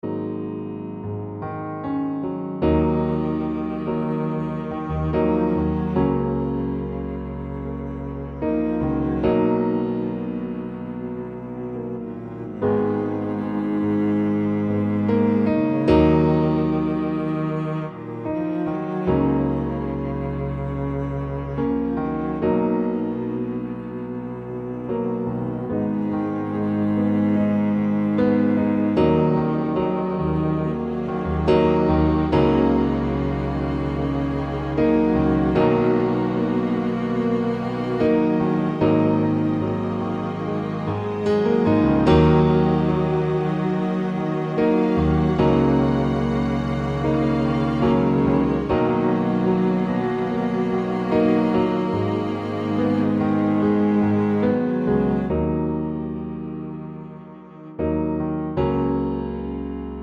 Acoustic Version Down 2 Semitones Pop (2010s) 5:38 Buy £1.50